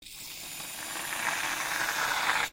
Звуки взбитых сливок
Звук взбитых сливок: поливаем торт или пирожное